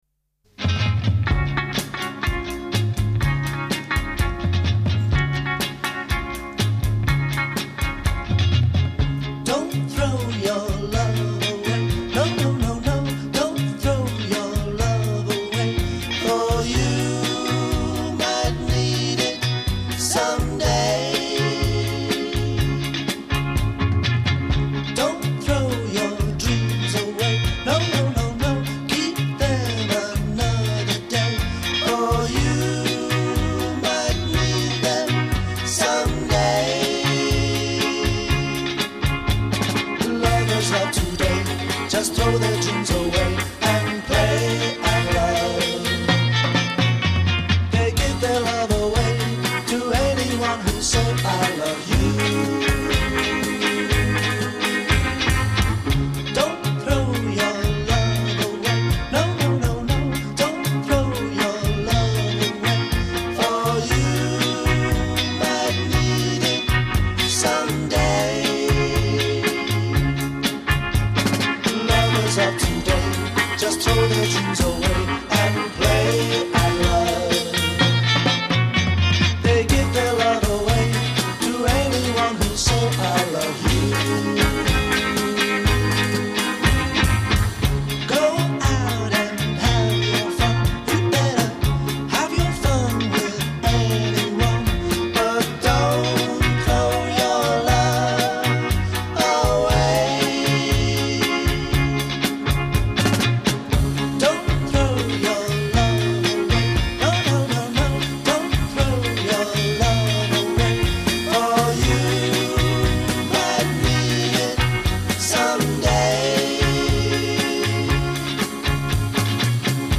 vocals and bass guitar
drums and vocals
intro 0:00 4 guitar with tremolo
8 double tracked vocals